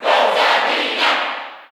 Crowd cheers (SSBU) You cannot overwrite this file.
Rosalina_&_Luma_Cheer_English_SSB4_SSBU.ogg